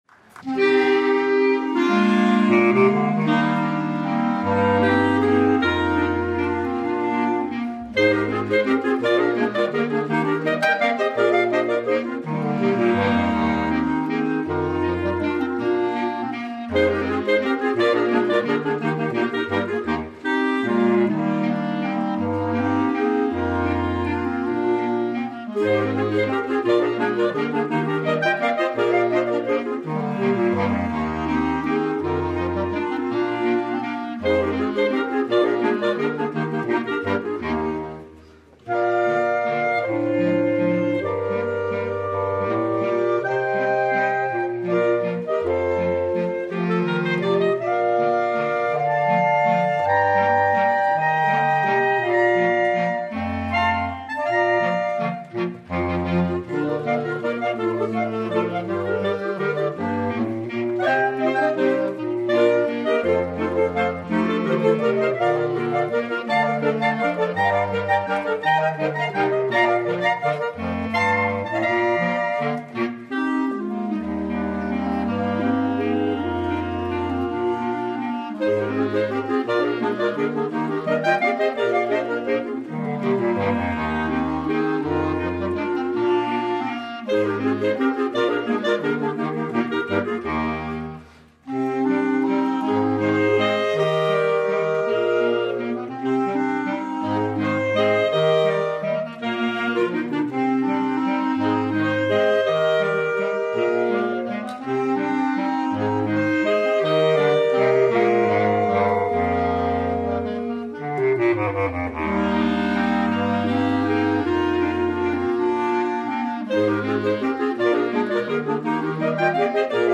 BR-Aufnahme 2025 im Freilandmuseum Neusath